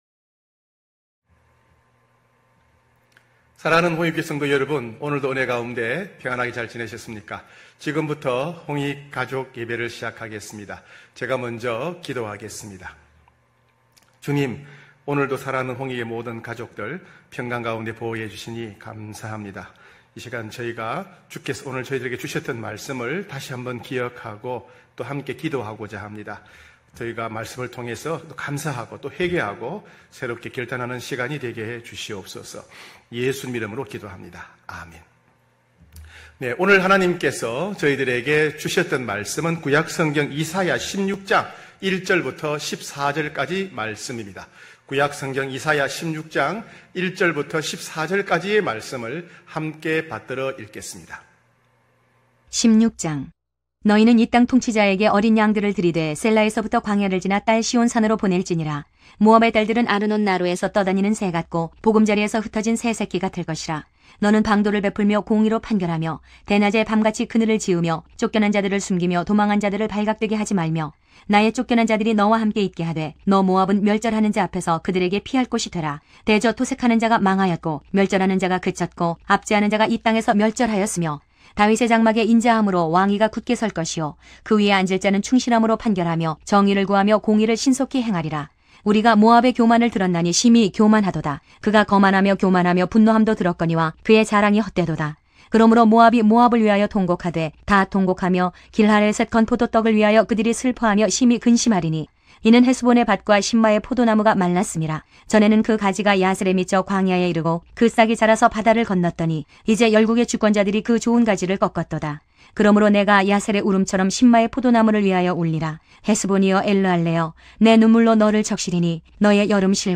9시홍익가족예배(8월1일).mp3